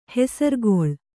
♪ hesargooḷ